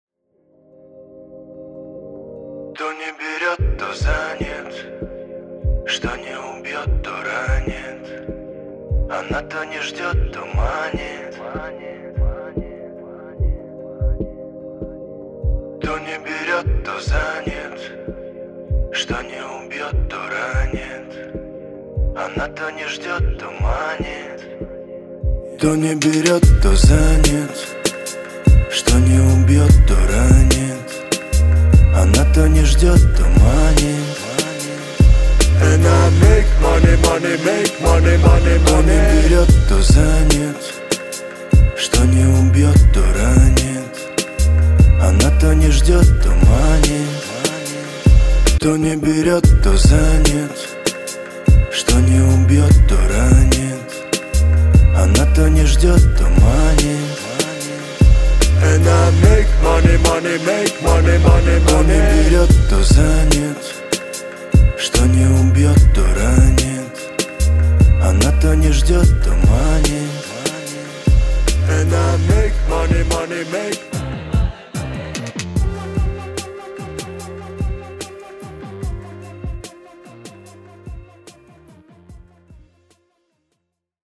• Качество: 320, Stereo
Хип-хоп
русский рэп
спокойные